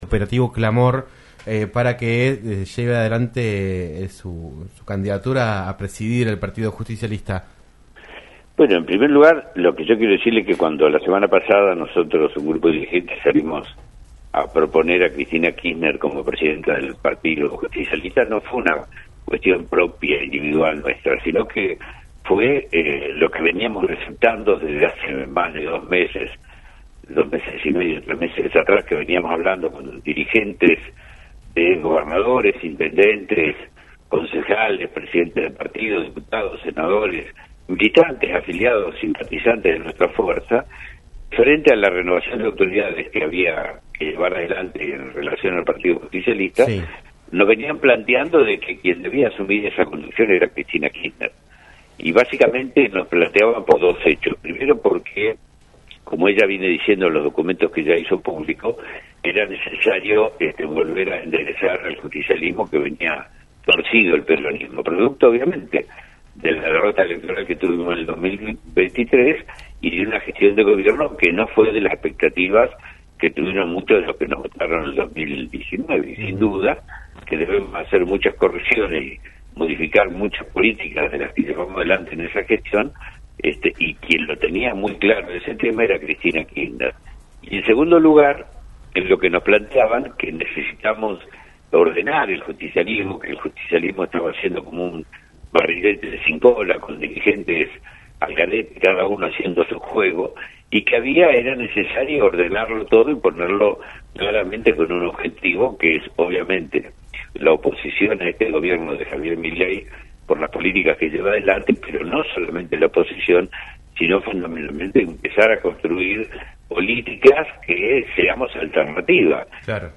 Oscar Parrilli, senador nacional de Unión por la Patria por la provincia de Neuquén, habló en "Un Millón de Guanacos" por LaCienPuntoUno sobre la decisión de Cristina Kirchner de ser candidata a presidir el Partido Justicialismo a nivel nacional.